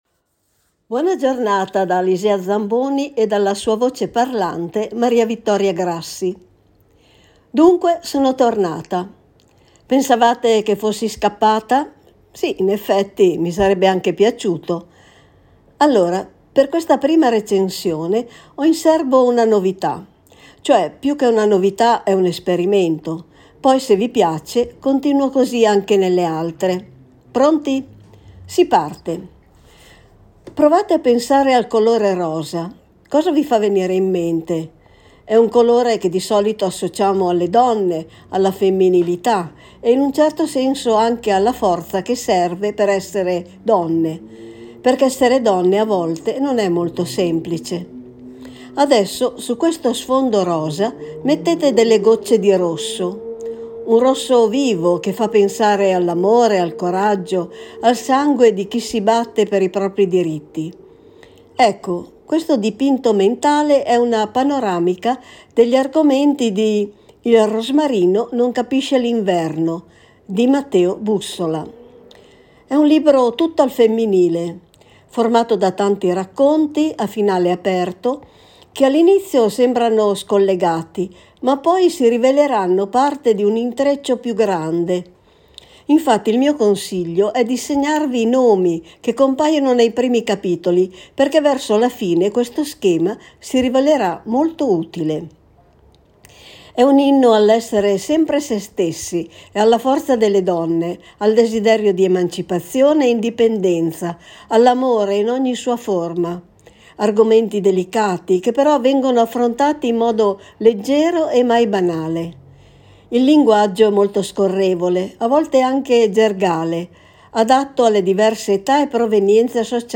Voce parlante